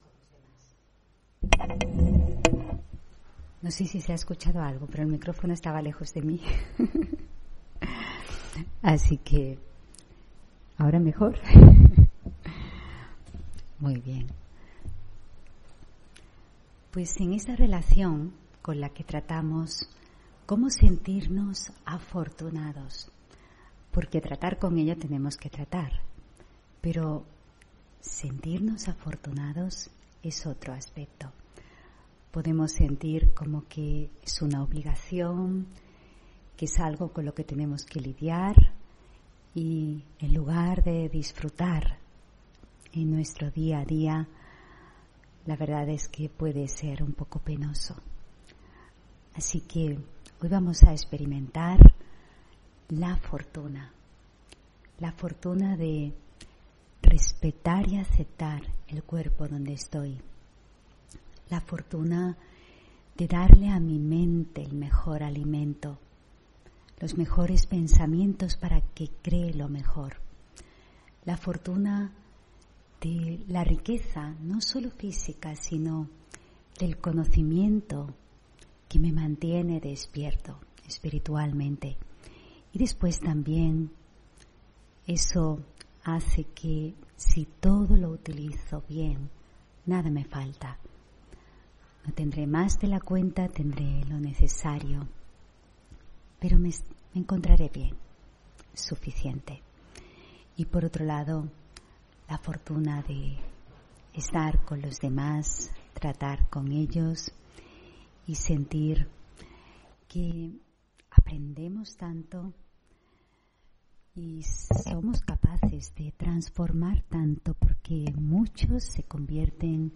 Meditación de la mañana: Mi fortuna